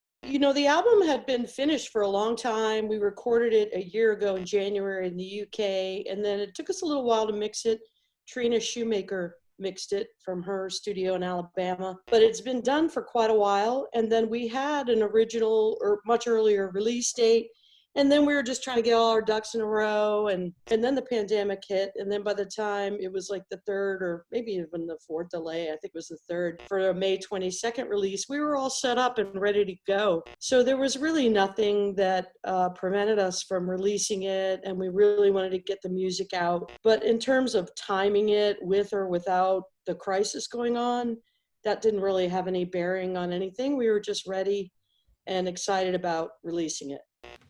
lifeblood: bootlegs: 2020: 2020-06-24: house shows series - the av club - the onion
03. talking with the crowd (0:24)